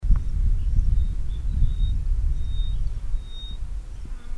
Pipit montagnard ( Anthus sylvanus )
Chant enregistré le 09 mai 2012, en Chine, province du Fujian, réserve de Dai Yun Shan.